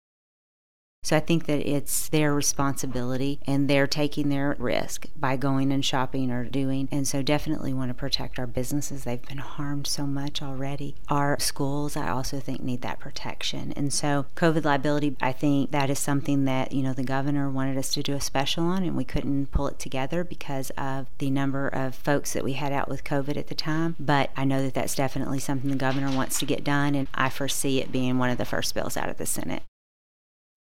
JEFFERSON CITY — State Sen. Holly Rehder, R-Scott City, discusses some of the legislation to receive committee hearings this week.